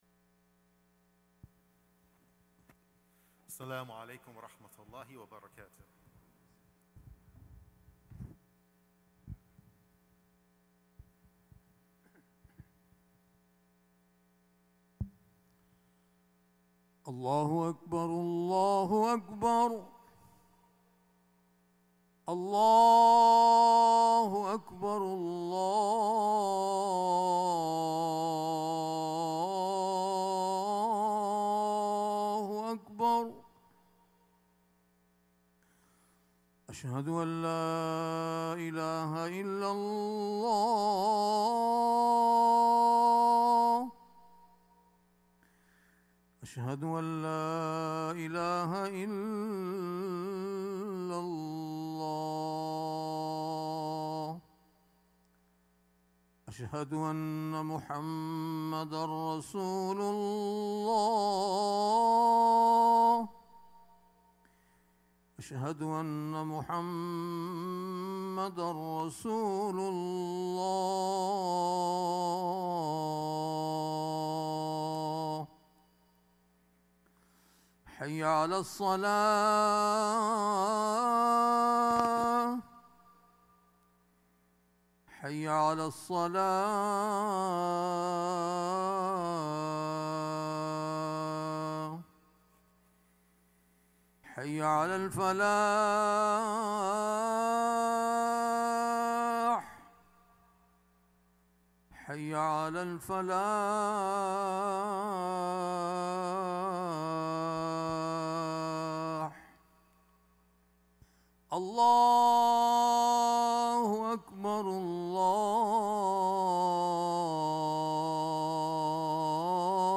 Friday Khutbah - "Time and Regret"